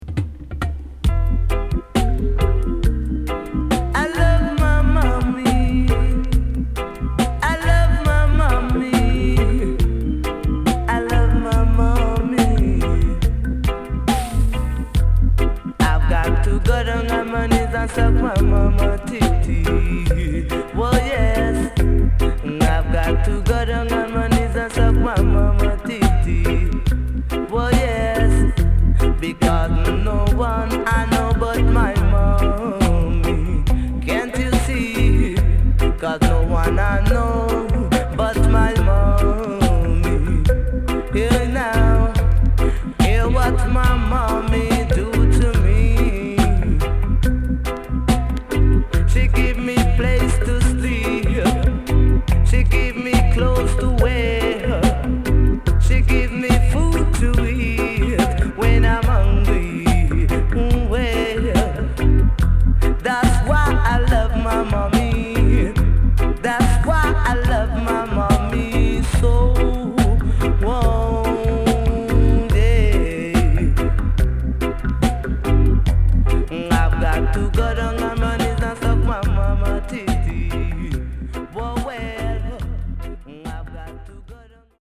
HOME > Back Order [DANCEHALL LP]
マイナー調のHeavyな曲が多いです。
SIDE A:少しチリノイズ入りますが良好です。